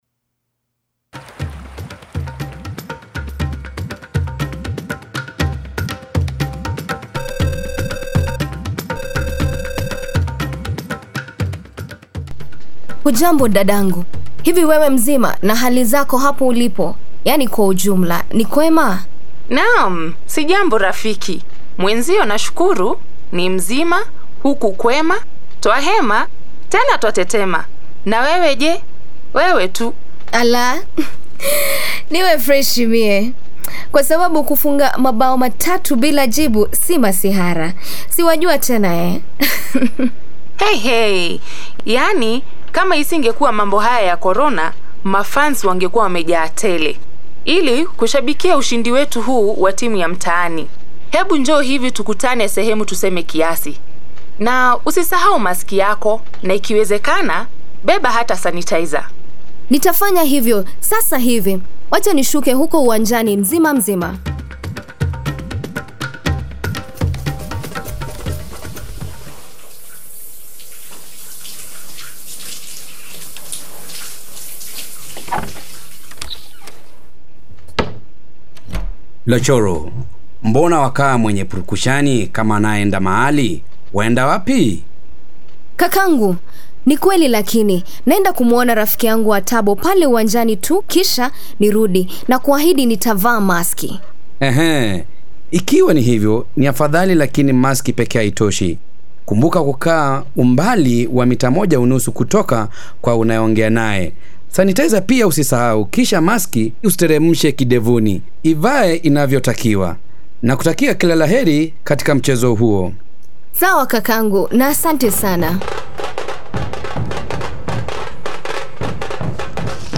Swahili drama by Kenya community Media Network in conjunction with Sports for Development Africa.
KISWAHILI-DRAMA-PORTS-GENDER-EQUILITY.mp3